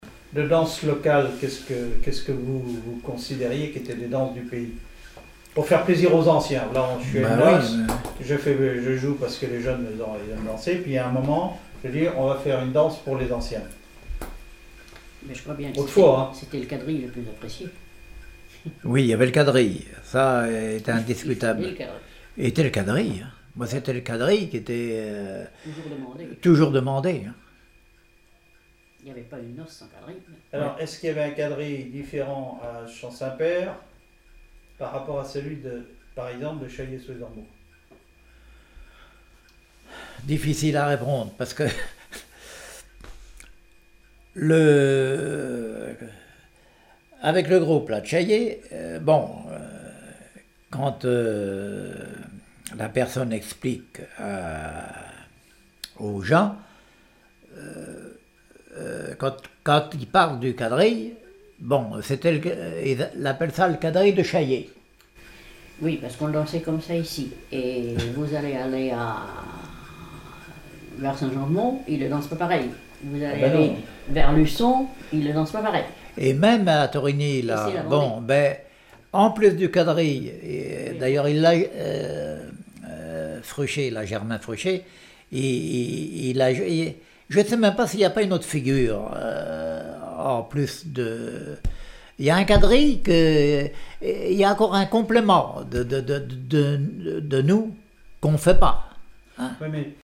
Témoignage comme joueur de clarinette
Catégorie Témoignage